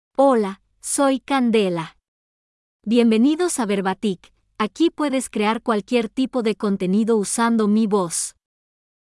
Candela — Female Spanish AI voice
Candela is a female AI voice for Spanish (Mexico).
Voice sample
Listen to Candela's female Spanish voice.
Female
Candela delivers clear pronunciation with authentic Mexico Spanish intonation, making your content sound professionally produced.